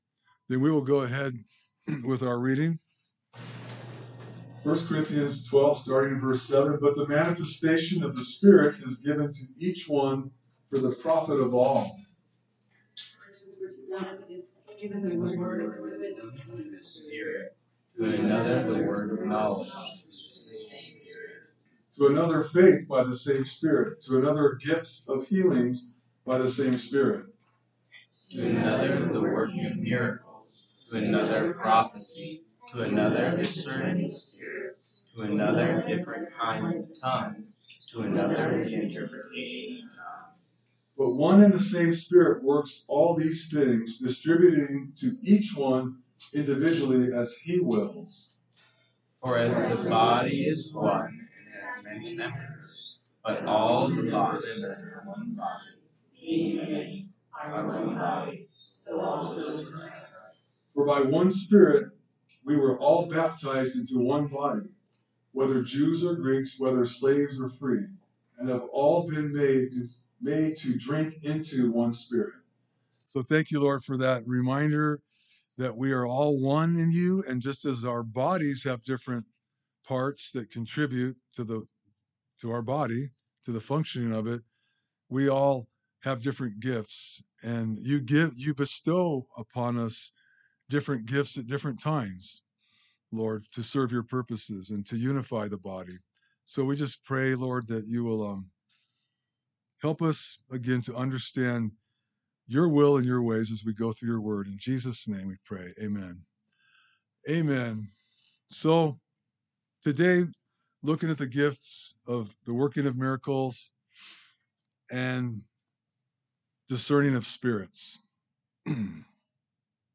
A message from the series "Gifts of the Spirit."